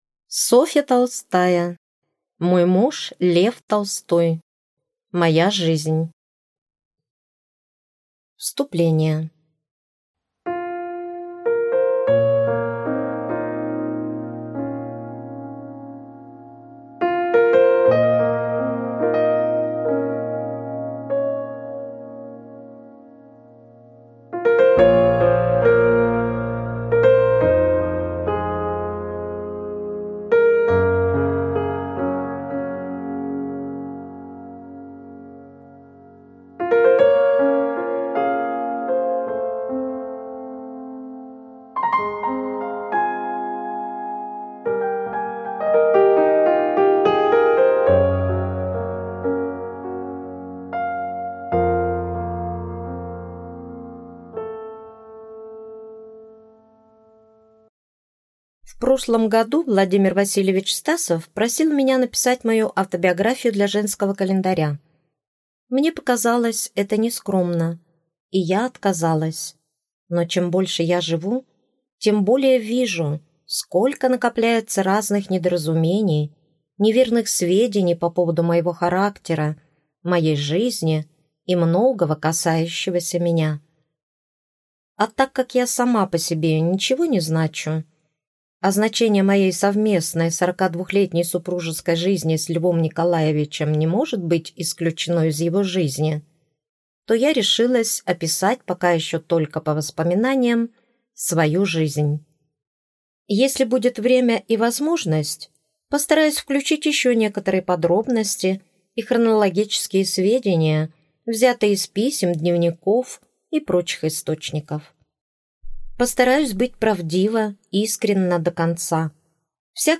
Аудиокнига Мой муж – Лев Толстой | Библиотека аудиокниг